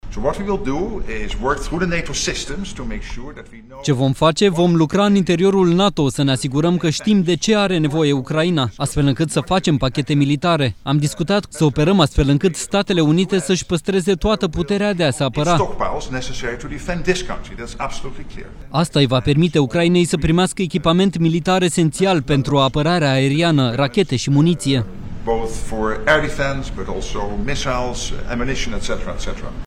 14iul-19-Rutte-tradus-ce-vom-face-ptr-Ucraina-.mp3